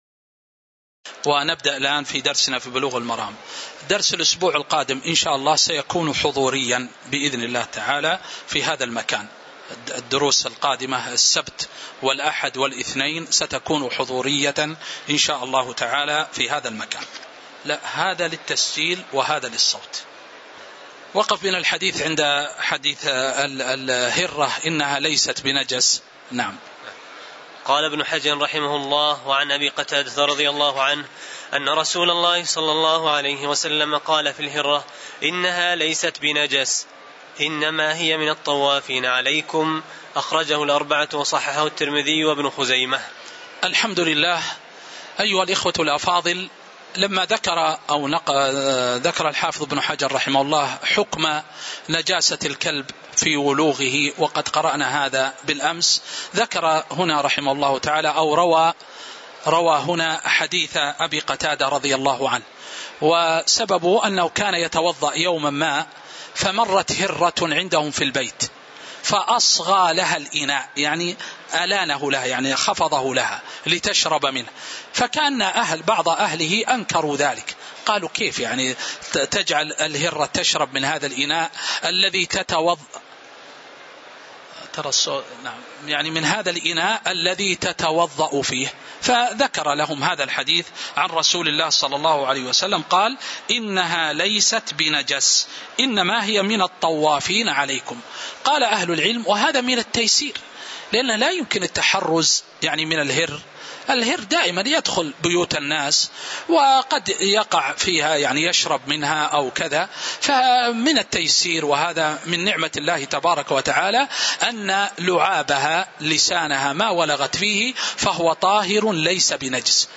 تاريخ النشر ١١ شوال ١٤٤٤ هـ المكان: المسجد النبوي الشيخ